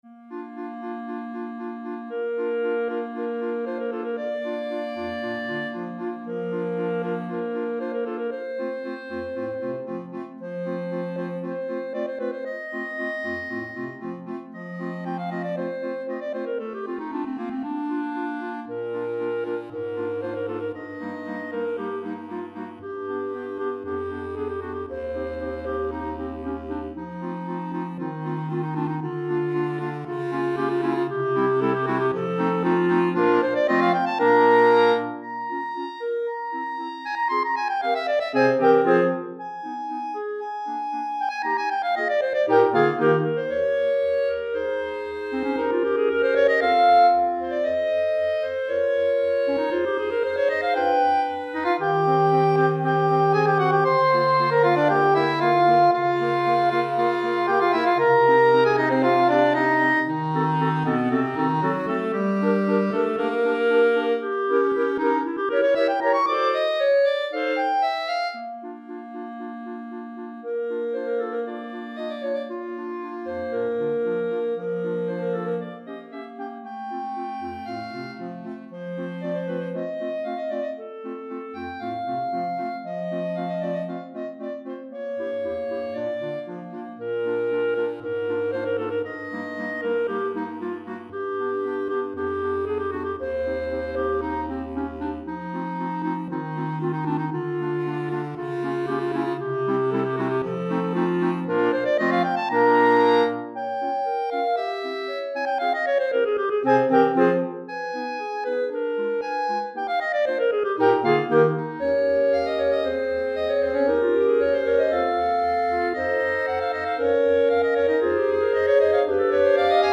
4 Clarinettes en Sib et Clarinette Basse